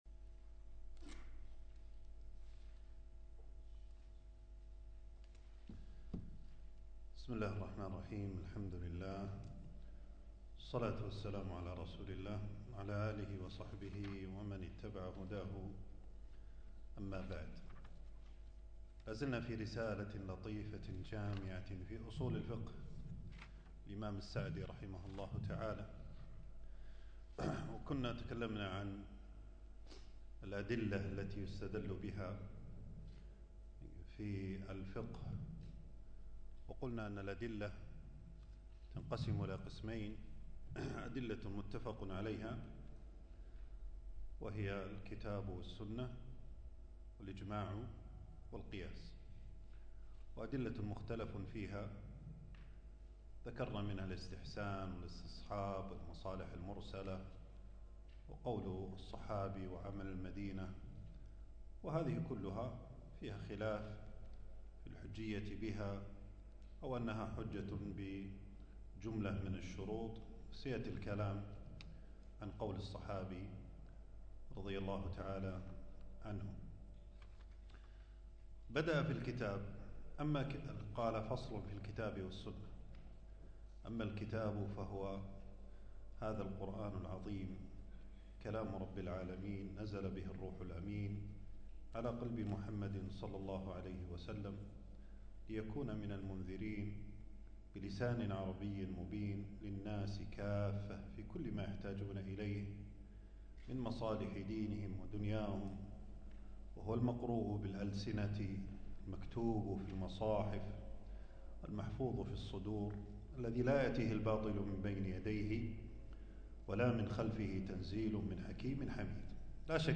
تنزيل تنزيل التفريغ العنوان: شرح رسالة لطيفة جامعة في أصول الفقه المهمة للعلامة السعدي. (الدرس الثاني والأخير) ألقاه
المكان: درس ألقاه يوم السبت 18 جمادى الأول 1447هـ في مسجد السعيدي.